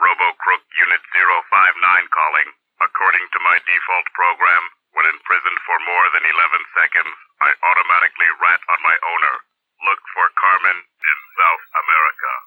Added all of Robocrook's jail calls.